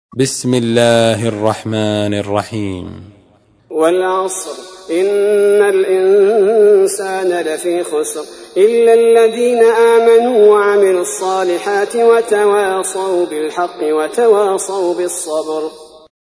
تحميل : 103. سورة العصر / القارئ عبد البارئ الثبيتي / القرآن الكريم / موقع يا حسين